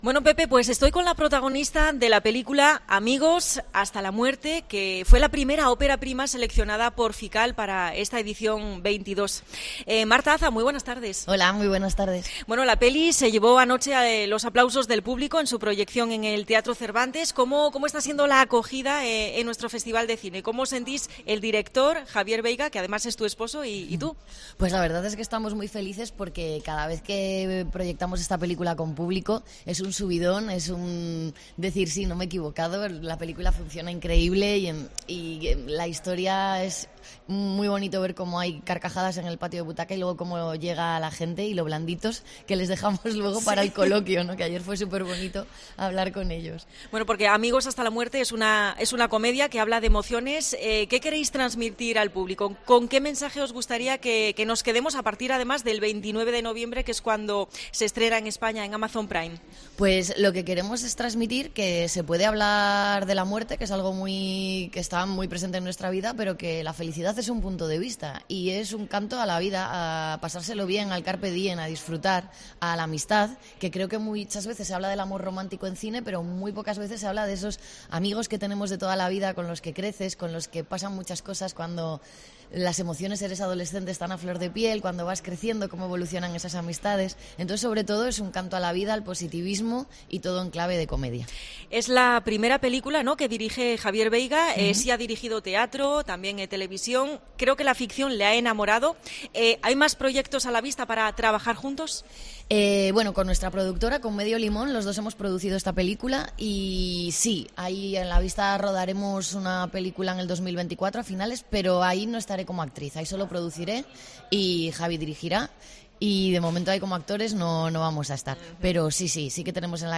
FICAL 2023: entrevista a Marta Hazas (actriz)